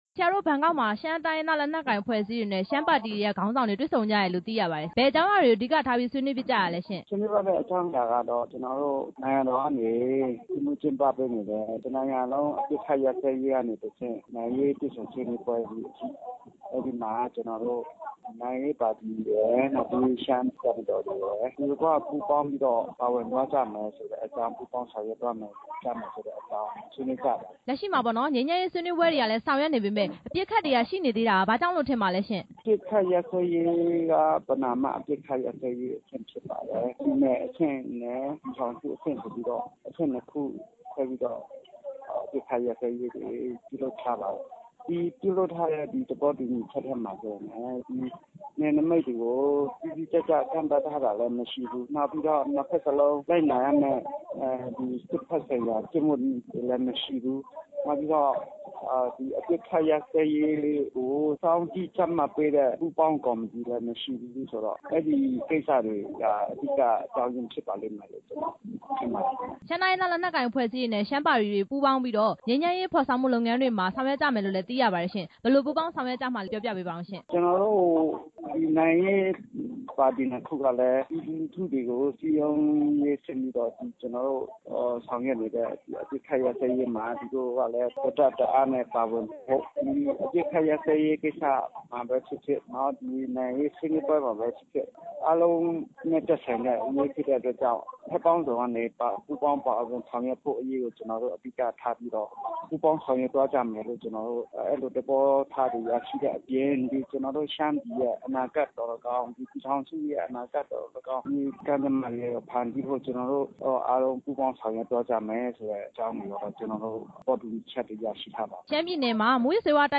ထိုင်းနိုင်ငံမှာ ရှမ်းခေါင်းဆောင်တွေ ဆွေးနွေးပွဲ မေးမြန်းချက်